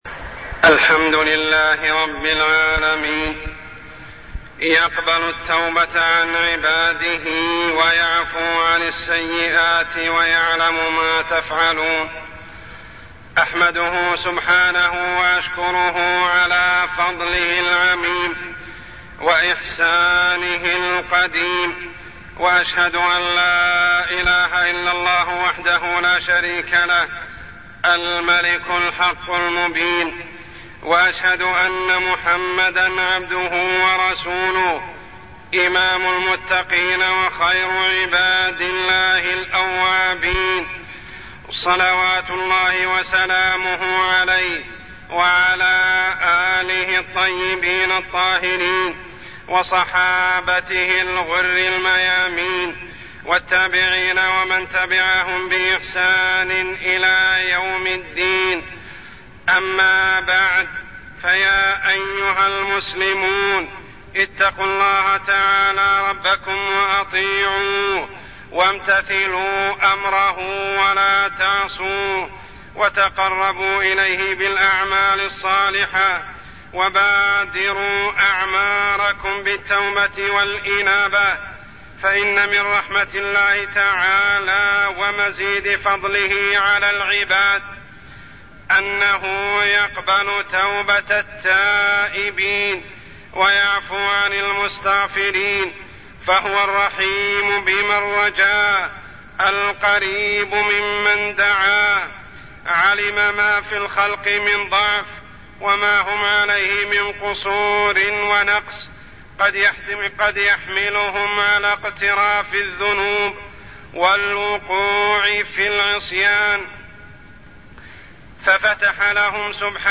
تاريخ النشر ١٧ ربيع الثاني ١٤٢٠ هـ المكان: المسجد الحرام الشيخ: عمر السبيل عمر السبيل الإستغفار